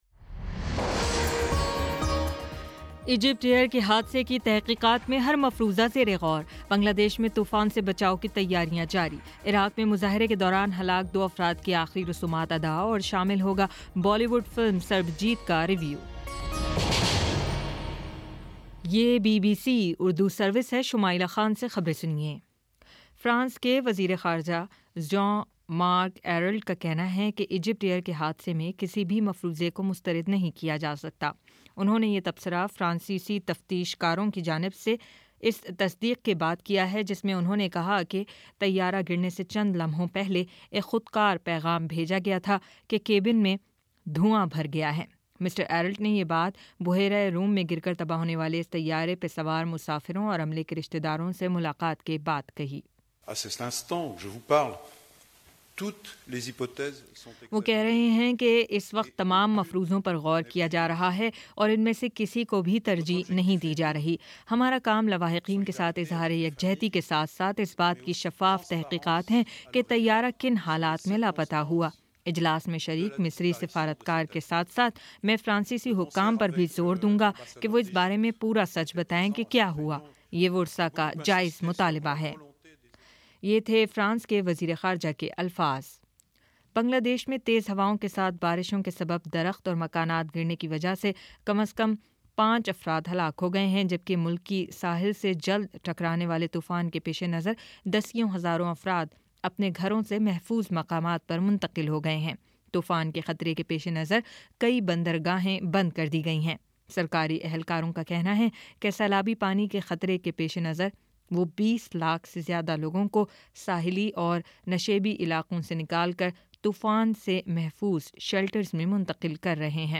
مئی 21 : شام چھ بجے کا نیوز بُلیٹن